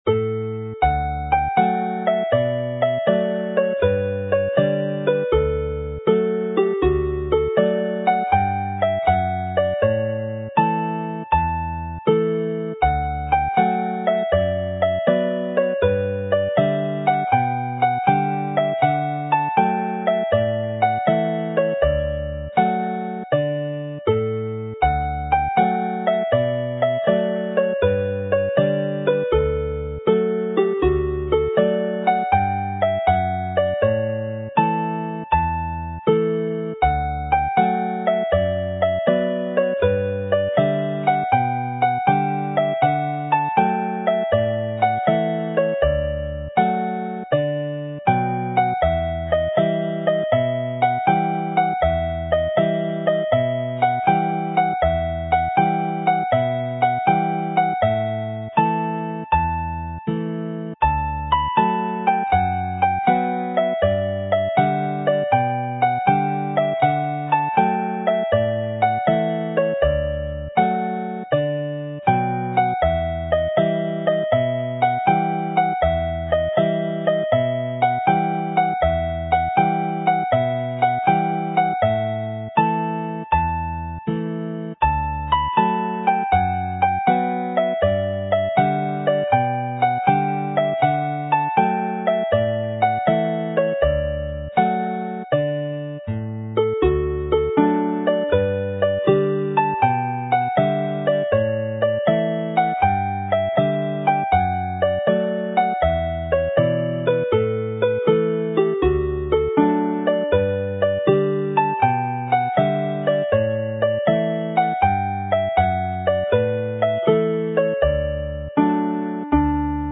Play slowly
This set of hornpipes is one of the happiest you could hear